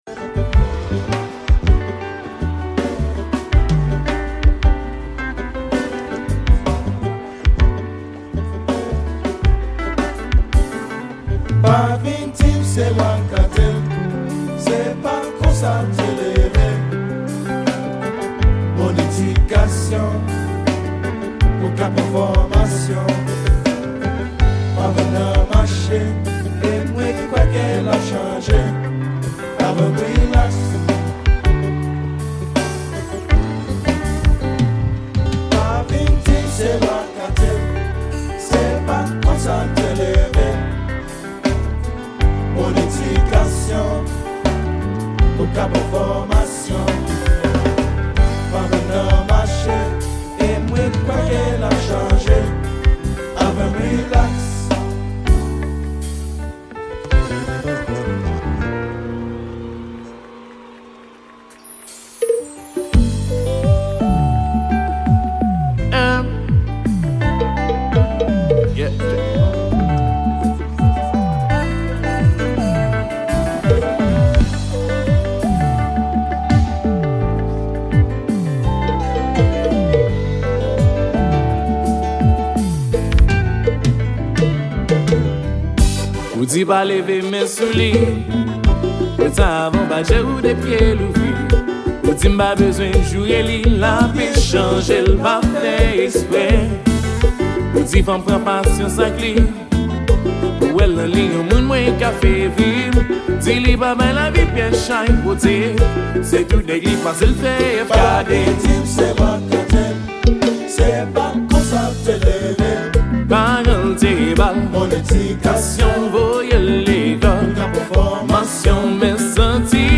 Genre: KONPA LIVE.